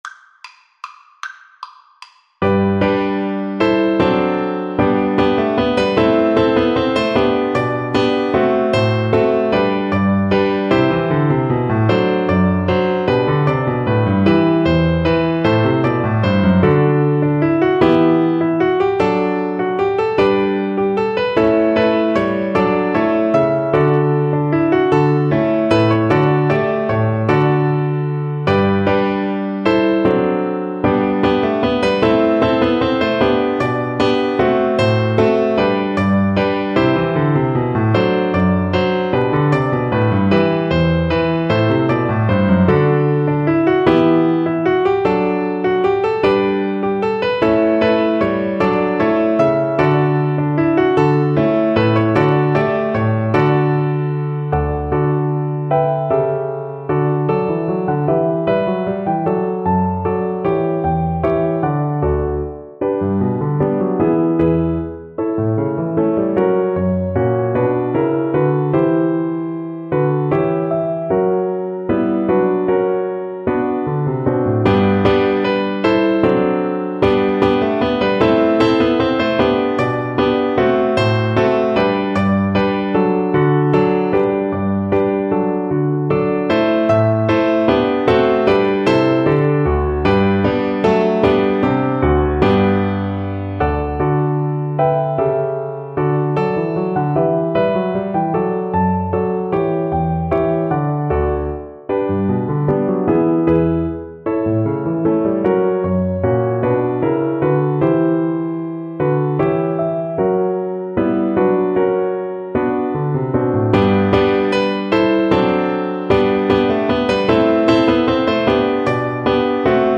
3/8 (View more 3/8 Music)
IV: Allegro =76 (View more music marked Allegro)
Classical (View more Classical Cello Music)